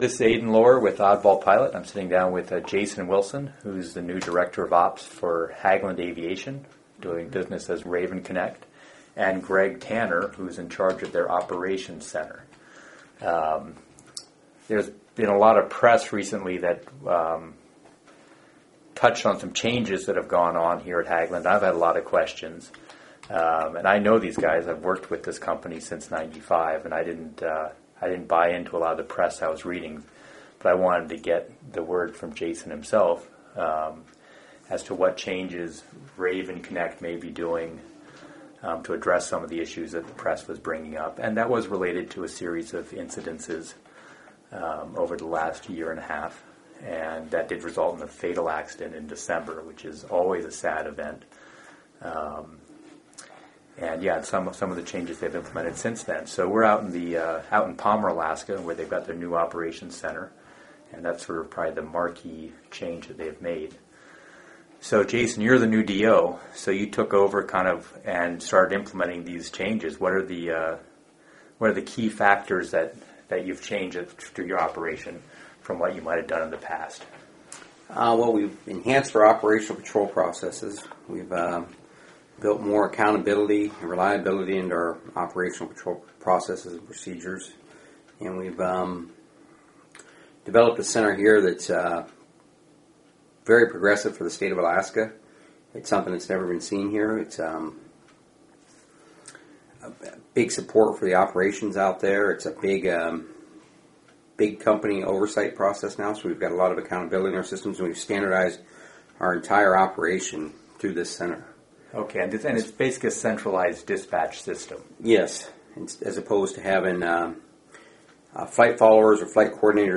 In this interview you’ll hear about:
hageland_ops_center_interview.mp3